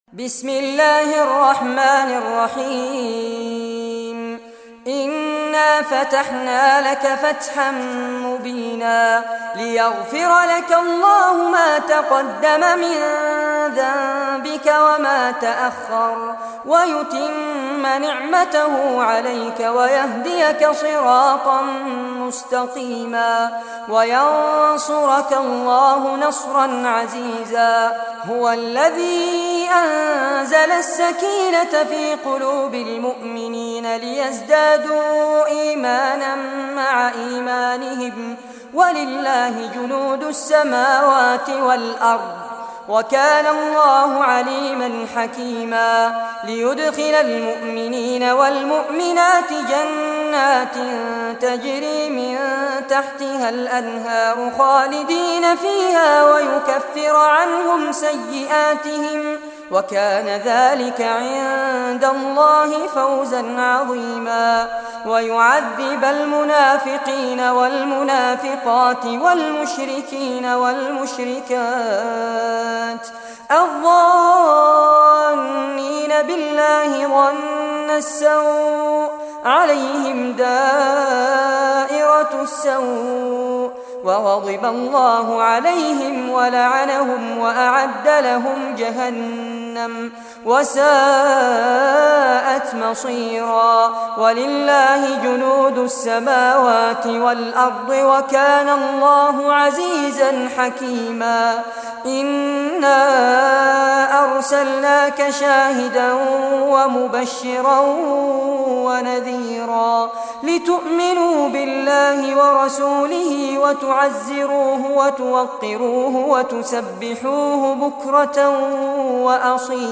Surah Al-Fath Recitation by Fares Abbad
Surah Al-Fath, listen or play online mp3 tilawat / recitation in Arabic in the beautiful voice f Sheikh Fares Abbad.